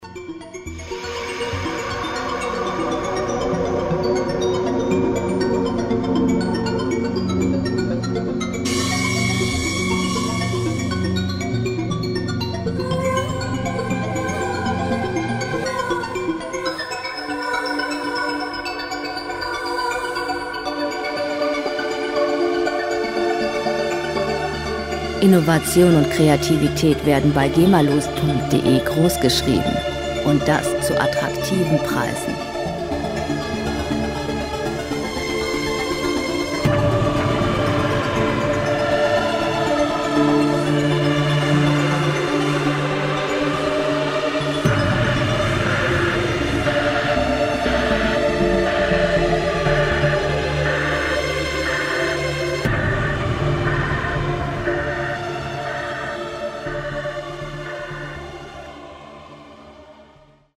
Elektronische Musik - Maschinen
Musikstil: Soundtrack
Tempo: 120 bpm
Tonart: D-Moll
Charakter: bedrohlich, desorientiert
Instrumentierung: Synthesizer, Chor, Streicher, Soundeffekte